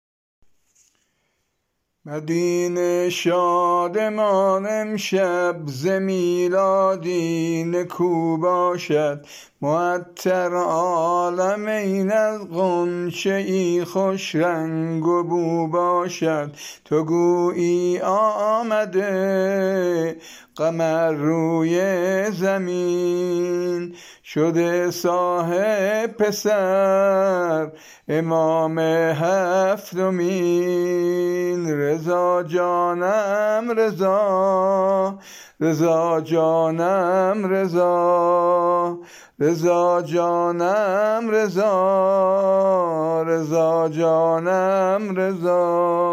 سرود میلاد امام علی بن موسی الرضا(ع)